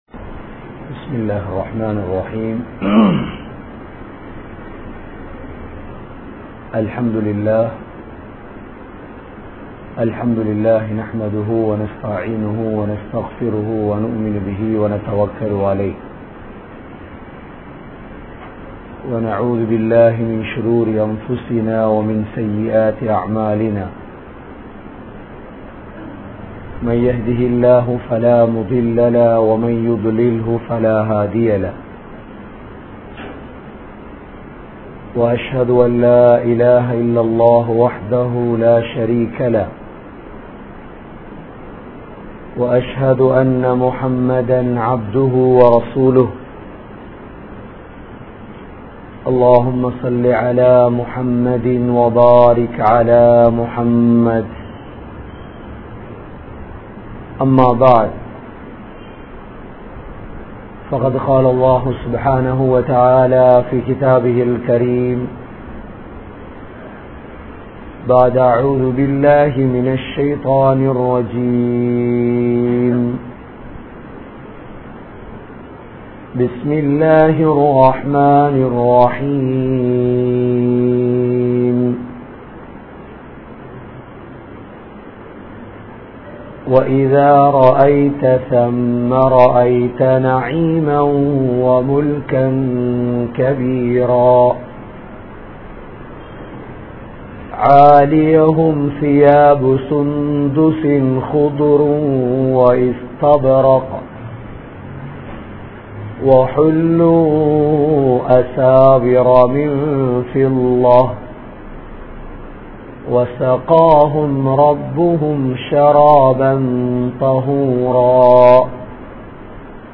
Thirunthugal | Audio Bayans | All Ceylon Muslim Youth Community | Addalaichenai